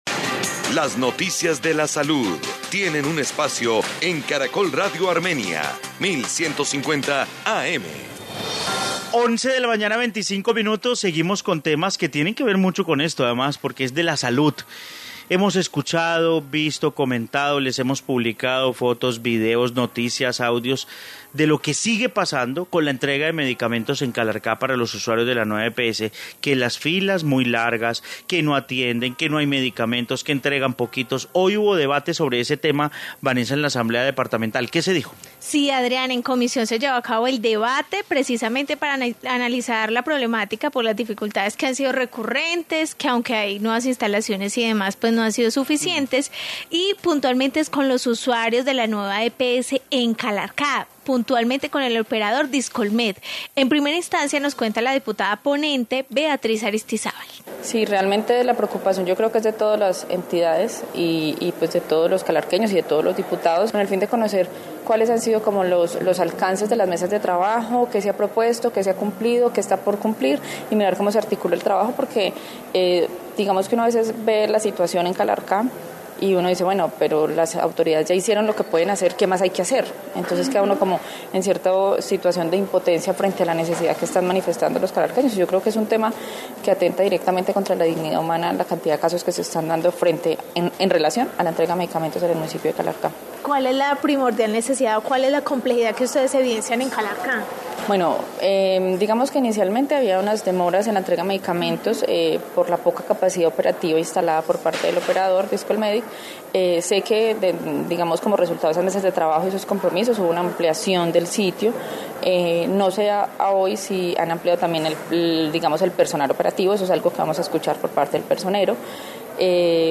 Informe debate medicamentos Calarcá